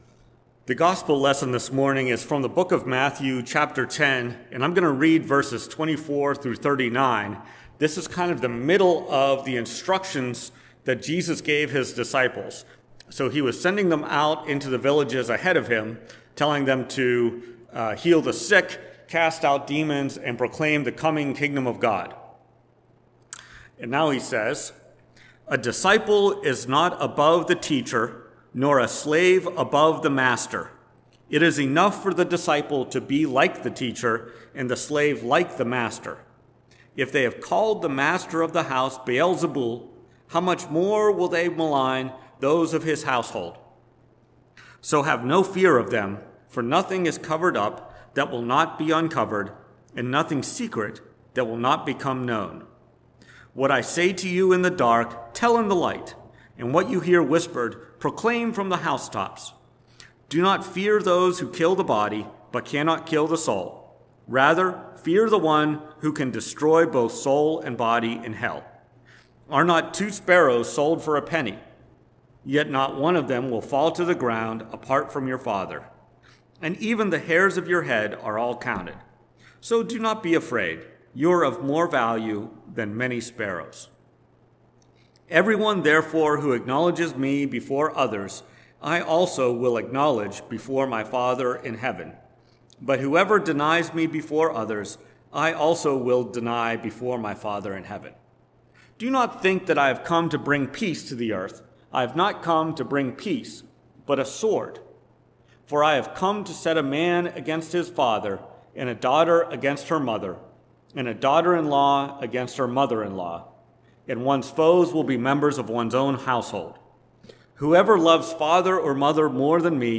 Preached at First Presbyterian Church of Rolla on June 25, 2023. Based on Matthew 10:25-39.